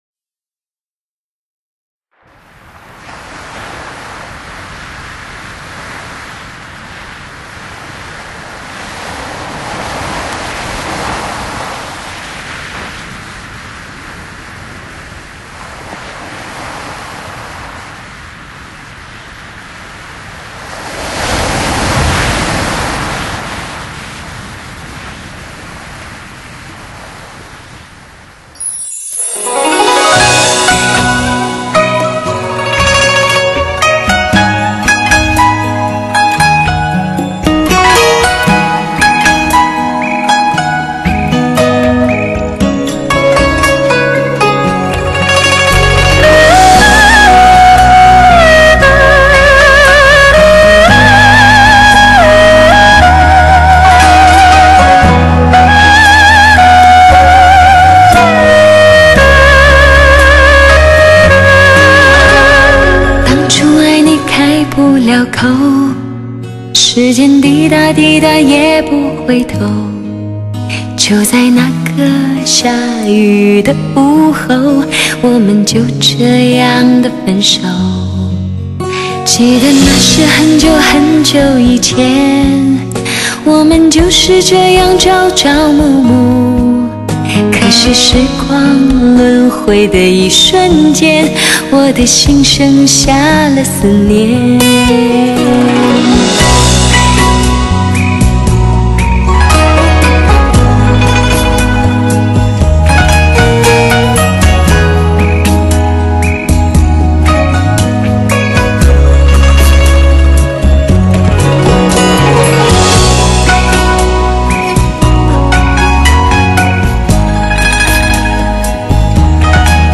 五彩韵色中。清新音色，磁性迷人歌喉，一声声，一首
SRS特效汽车，全方位360度，环绕AUTO SOUND专业HIFI